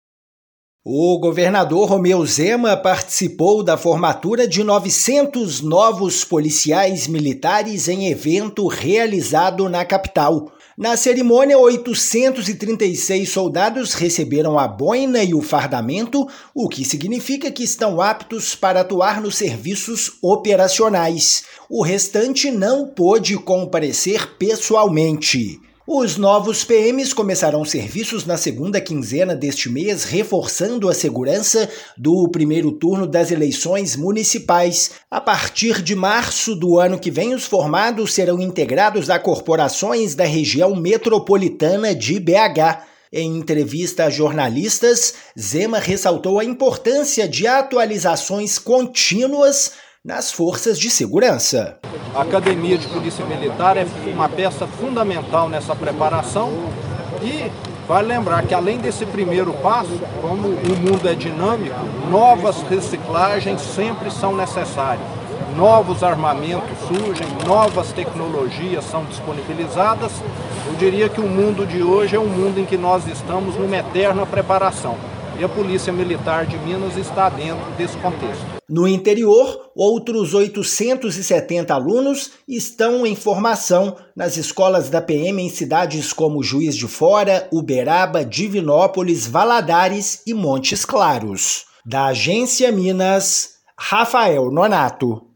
Efetivo começa a atuar este mês no primeiro turno das eleições; no interior, outros 870 alunos estão em formação nas escolas da PM em cidades como Juiz de Fora, Uberaba, Divinópolis, Valadares e Montes Claros. Ouça a matéria de rádio.